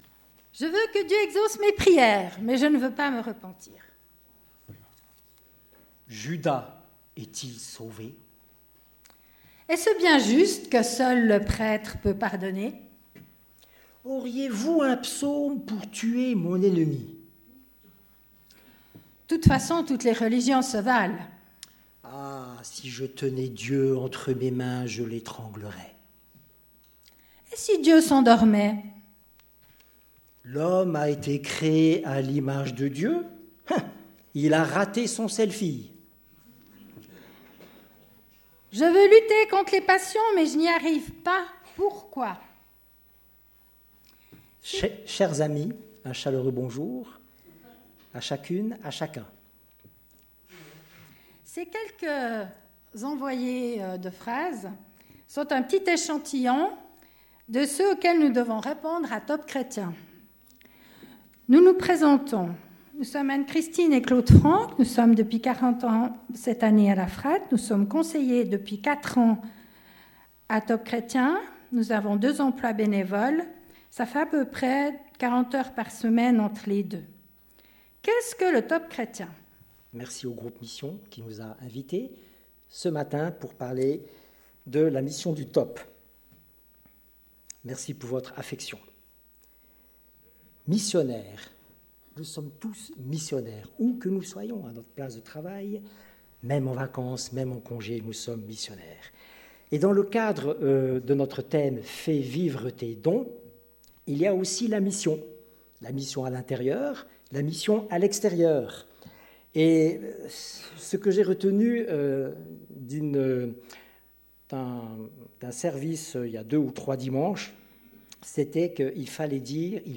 Culte du 25 février 2018 « Ministère Top chrétien »